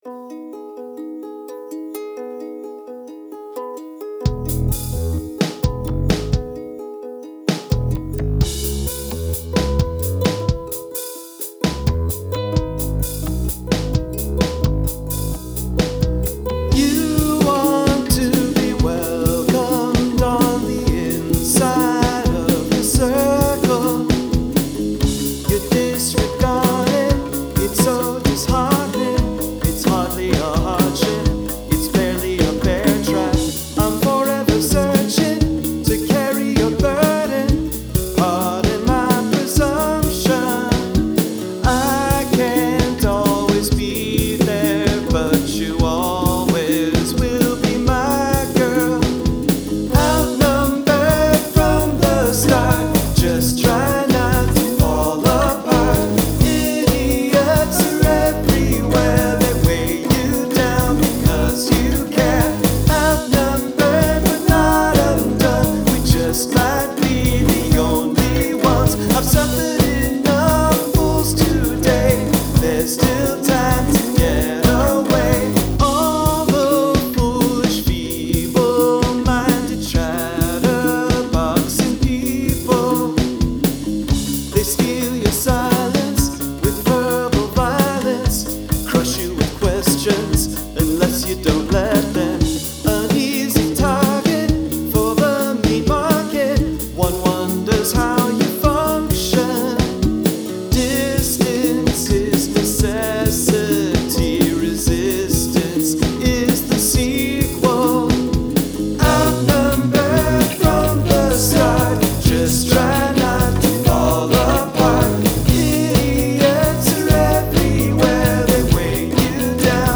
Odd Time Signature